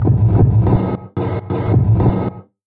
失控的Lofi Boom Bap鼓 90 BPM
描述：非常深，有一点破碎的Disortion... 反馈说，如果你使用
标签： 90 bpm Hip Hop Loops Drum Loops 459.61 KB wav Key : Unknown
声道立体声